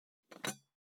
198,テーブル等に物を置く,食器,グラス,コップ,工具,小物,雑貨,コトン,トン,ゴト,ポン,ガシャン,ドスン,ストン,カチ,タン,バタン,スッ,
効果音物を置く